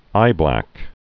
(īblăk)